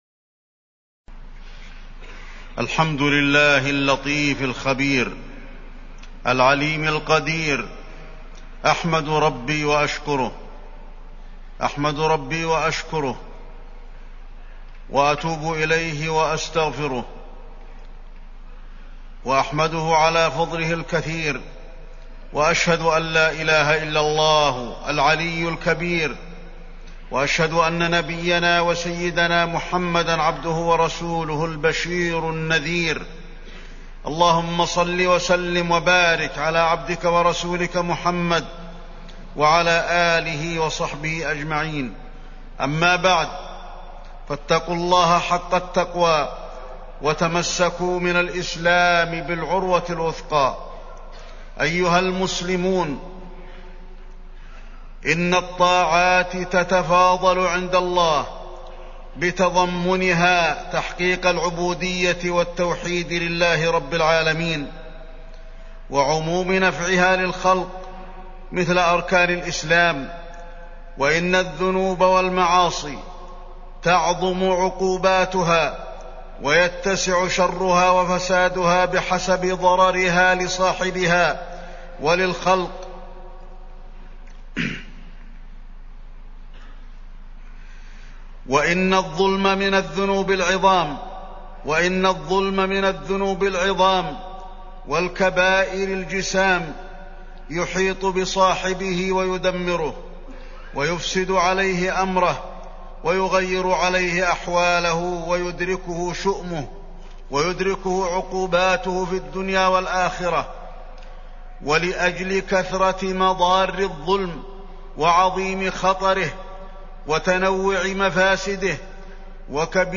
تاريخ النشر ٣ ذو القعدة ١٤٢٧ هـ المكان: المسجد النبوي الشيخ: فضيلة الشيخ د. علي بن عبدالرحمن الحذيفي فضيلة الشيخ د. علي بن عبدالرحمن الحذيفي الظلم